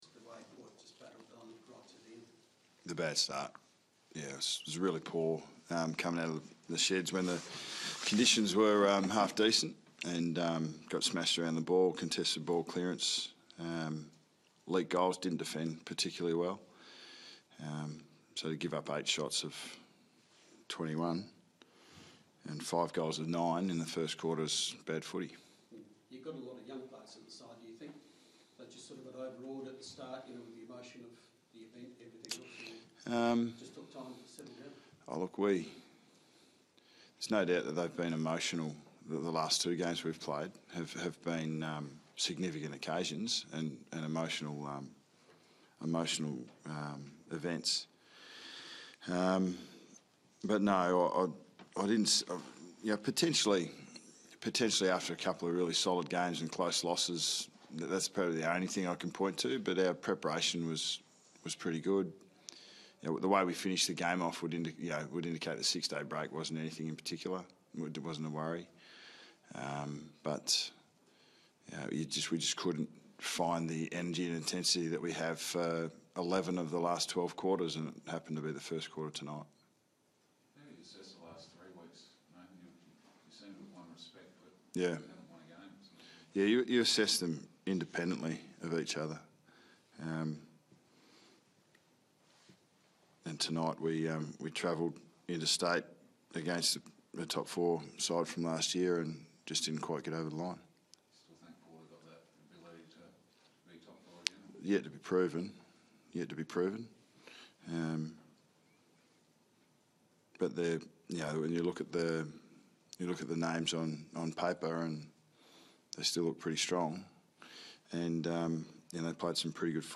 Watch coach Nathan Buckley take questions from the media following Collingwood's loss to Port Adelaide in round 15.